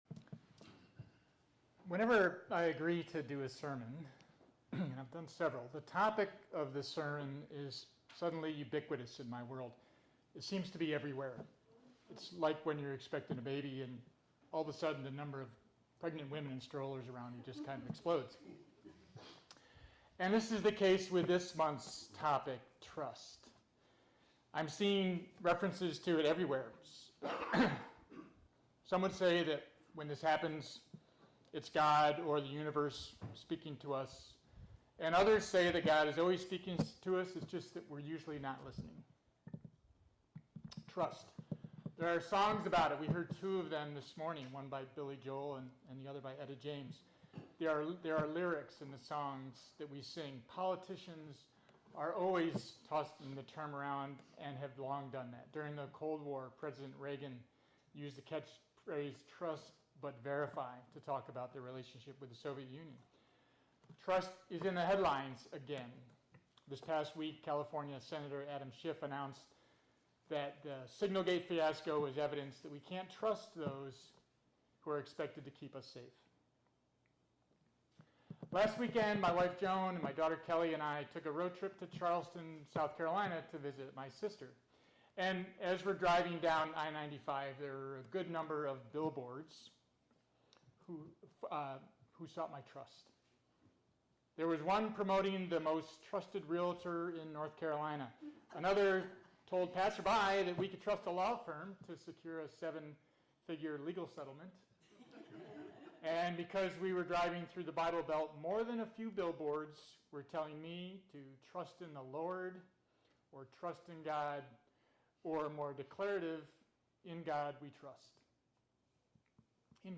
This sermon explores the nature of trust by distinguishing it from faith, defining trust as a confidence built through consistent action and evidence. The speaker highlights a global decline in trust toward major institutions, noting how this shift fosters social grievance and highlights the vital role of nonprofits as ethical leaders.